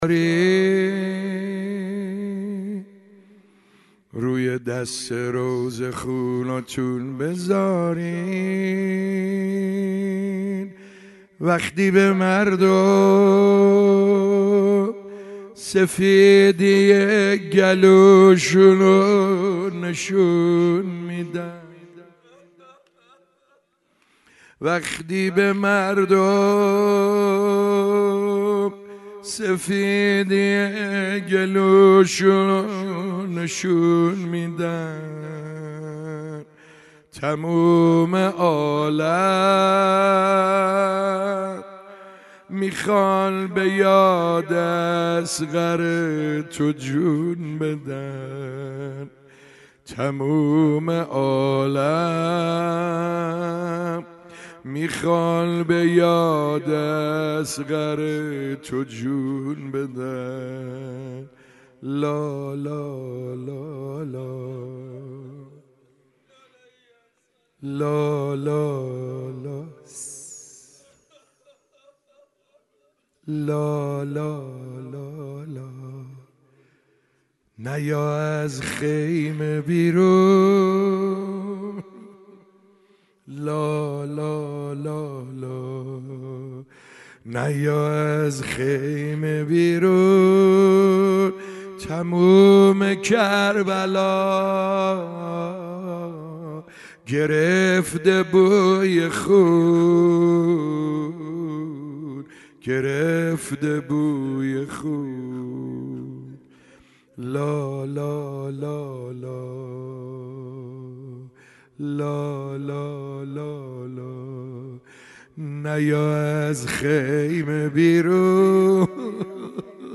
روضه خوانی - روز هفتم محرم 1445 - سعید حدادیان - حسینیه حق شناس